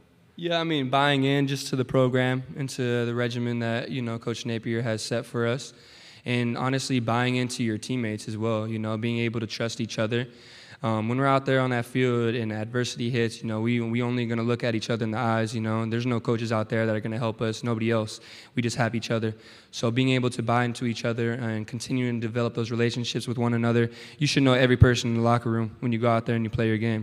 Media Days are being held at the Nashville Grand Hyatt Hotel in Nashville, Tennessee.